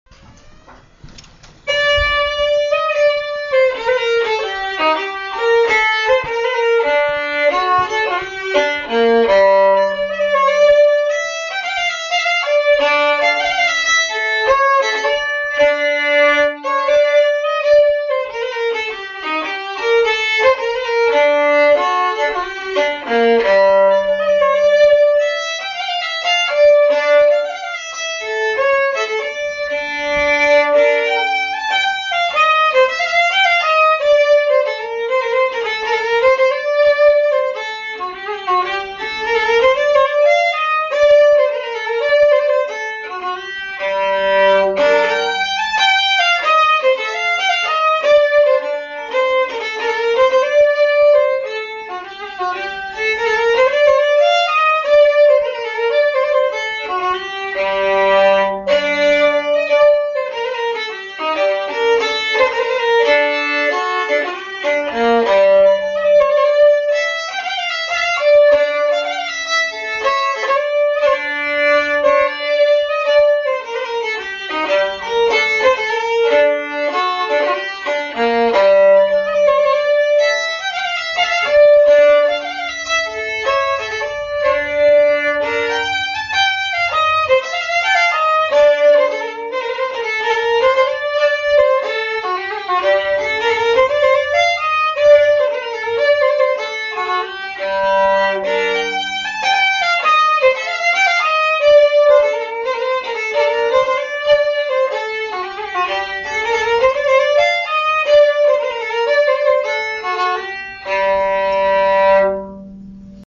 Polska från Alfta